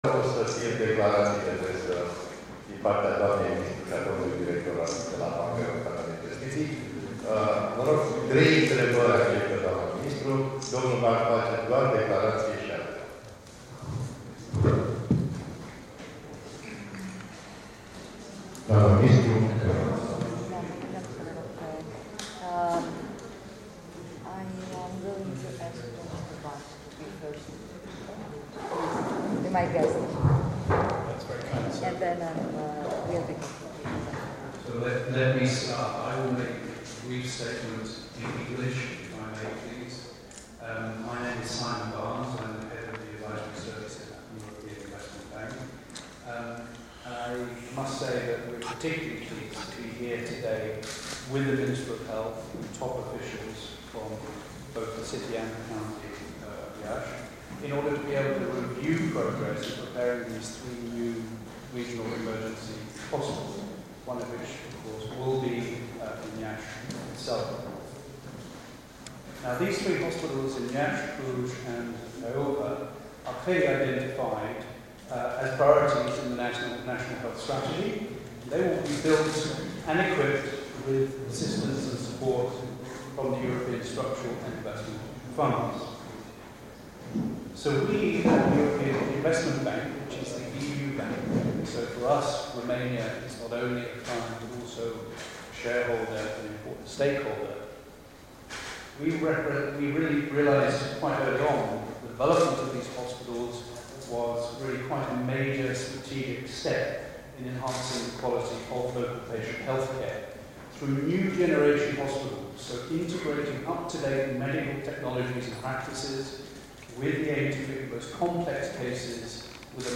Declaratii ministrul Sanatatii la Iasi - Radio Hit
Astazi 13 iulie, în Sala Henri Coandă a Palatului Culturii a avut loc o întâlnire de lucru dedicată prezentării, analizării și definitivării unor elemente tehnice decisive pentru implementarea proiectului Spitalului Regional de Urgență de la Iași.